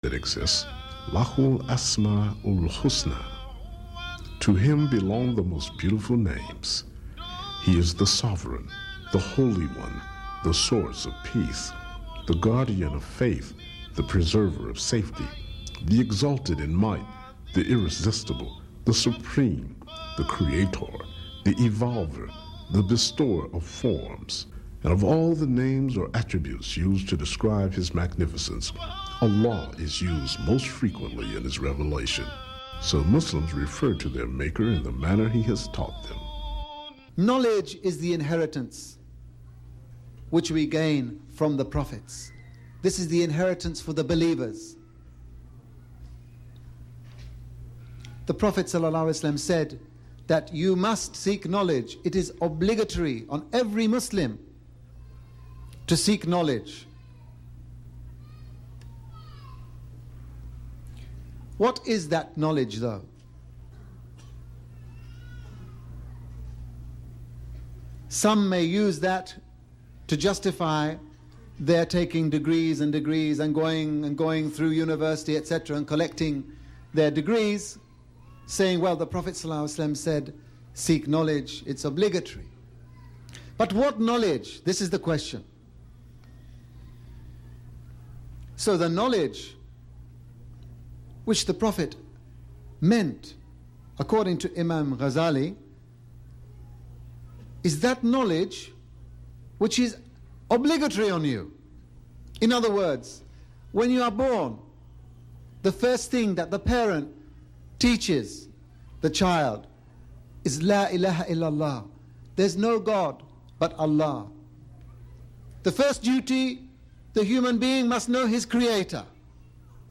This is a collection of interviews with Americans of different racial, ethnic and religious backgrounds who explain why they have chosen to become Muslim, embracing the faith of over 1 billion humans worldwide.